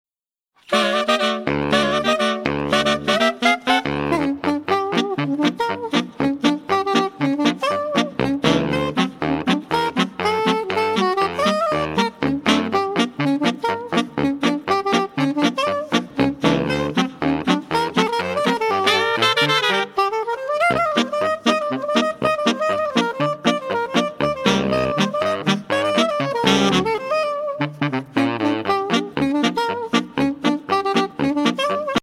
4 Saxophone (SATBar)